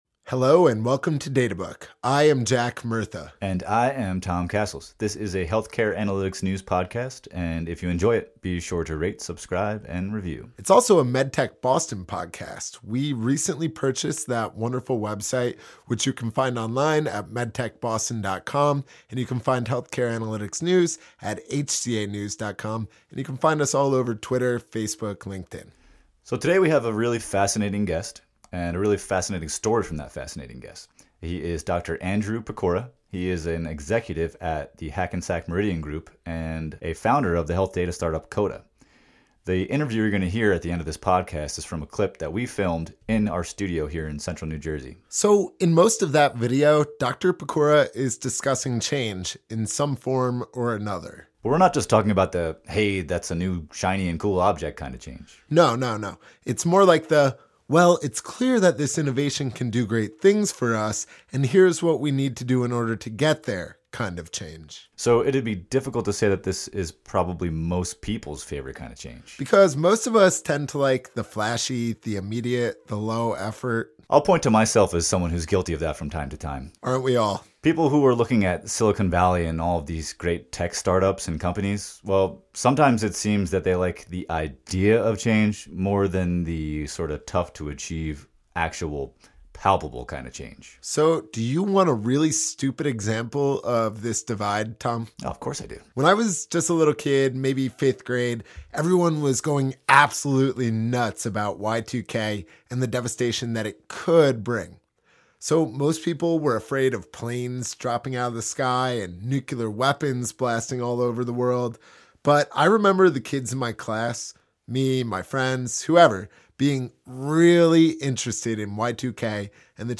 Featuring the story of Ascension Health and a conversation